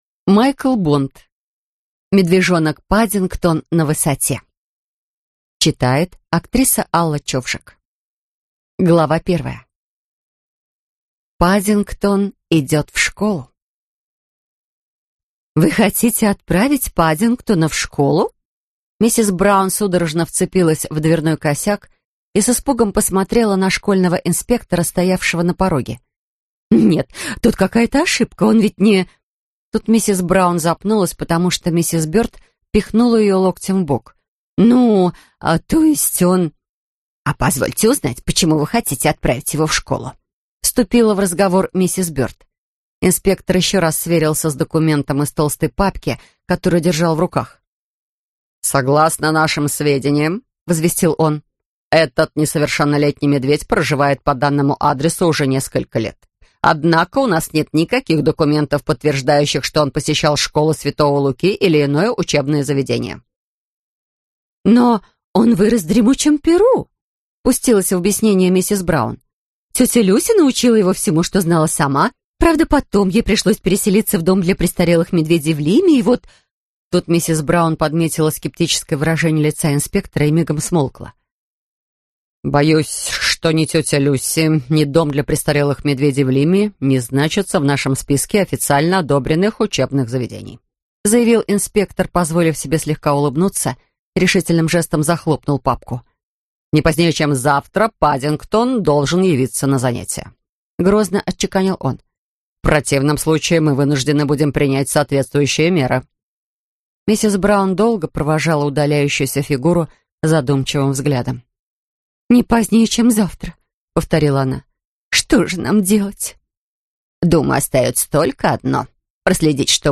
Аудиокнига Медвежонок Паддингтон на высоте | Библиотека аудиокниг